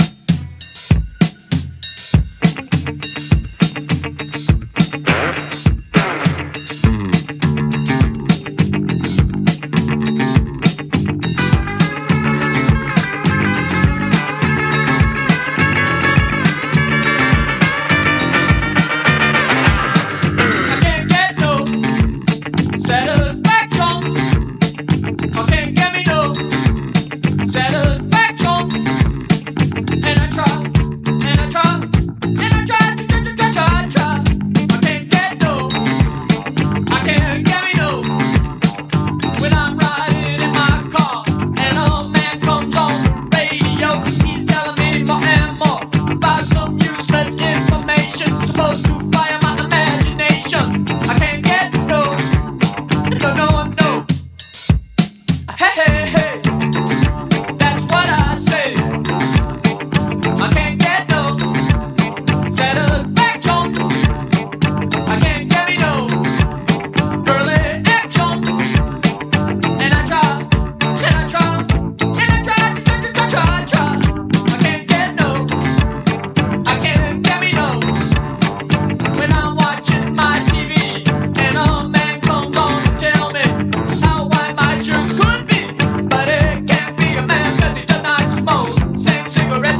ROCK / 70'S
荒削りながらもパワフルに突き進むギター・サウンド＆オルガンが◎な
繊細で美しいメロディーが鳥肌モノの